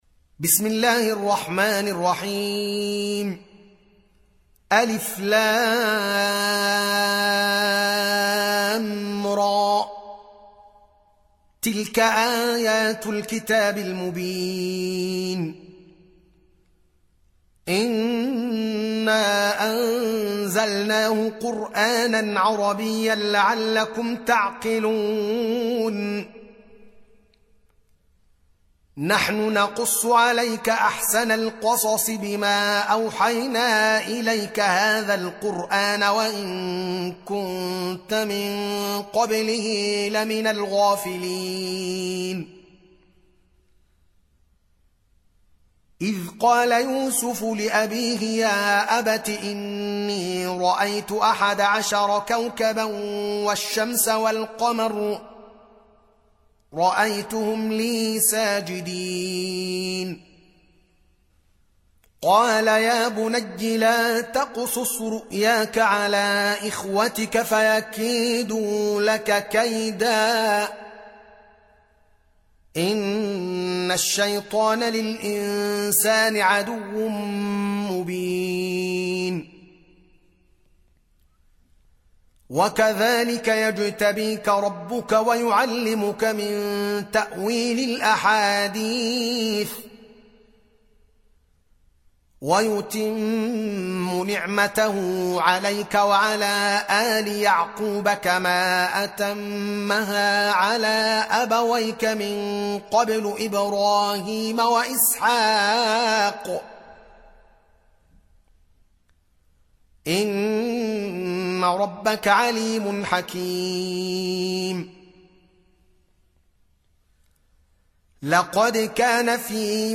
12. Surah Y�suf سورة يوسف Audio Quran Tarteel Recitation
Surah Repeating تكرار السورة Download Surah حمّل السورة Reciting Murattalah Audio for 12.